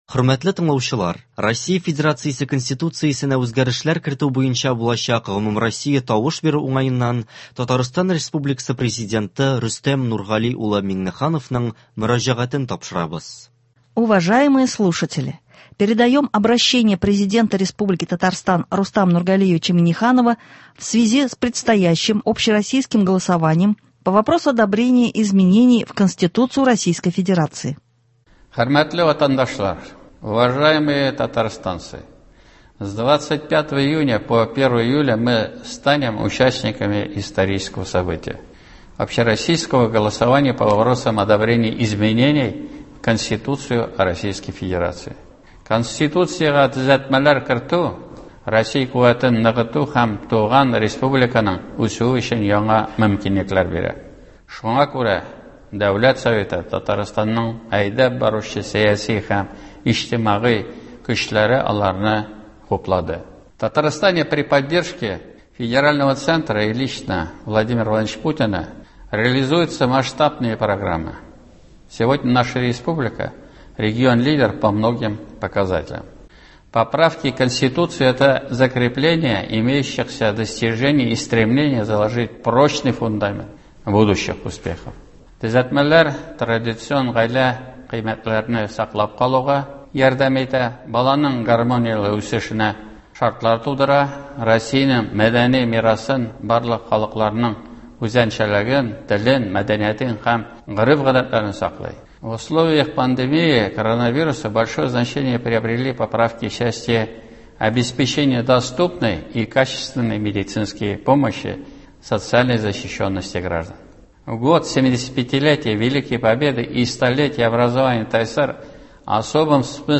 Обращение Президента Республики Татарстан Рустама Нургалиевича Минниханова в связи с предстоящим Общероссийским голосованием по вопросу одобрения изменений в Конституцию Российской Федерации.